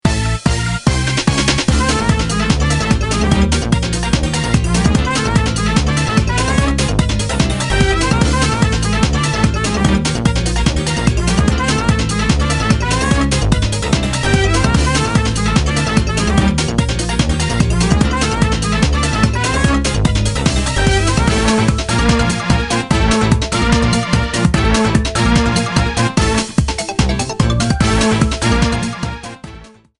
minigame theme rearranged